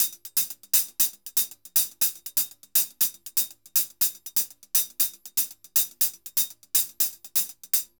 HH_Baion 120_2.wav